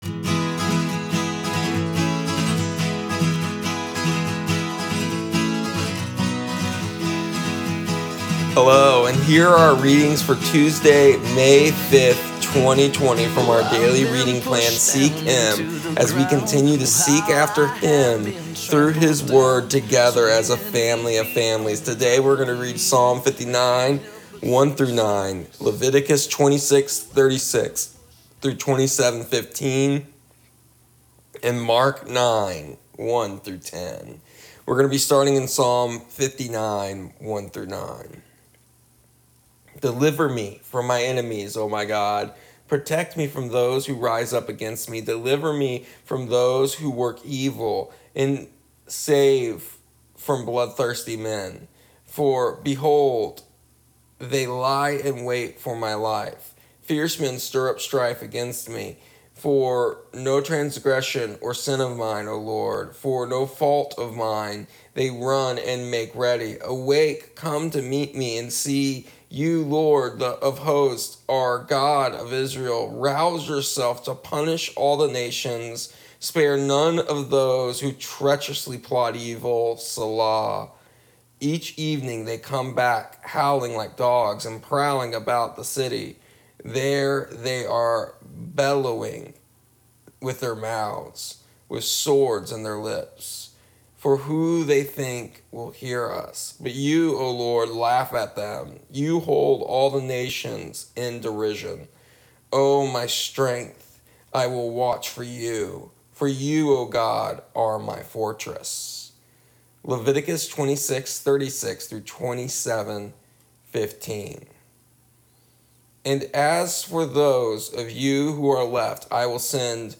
Here are our daily readings on audio for May 5th, 2020.